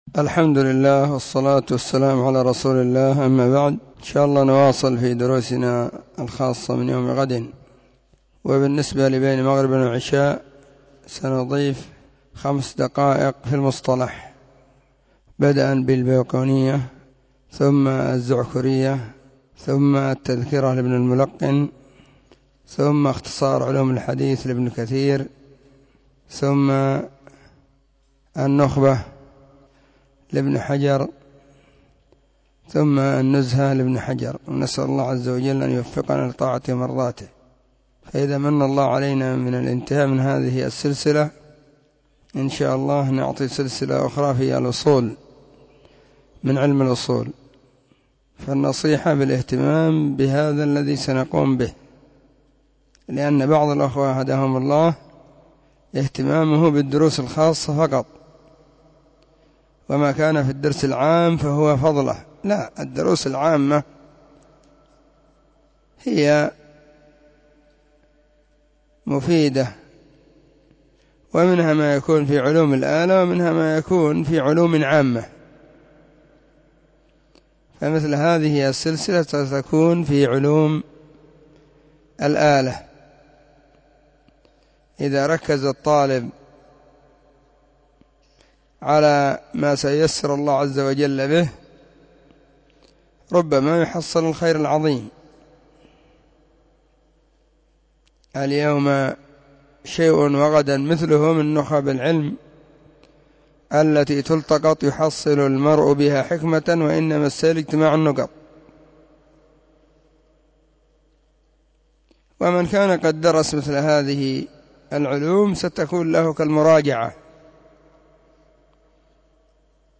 📢 مسجد – الصحابة – بالغيضة – المهرة، اليمن حرسها الله.